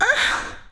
Chant (Metro).wav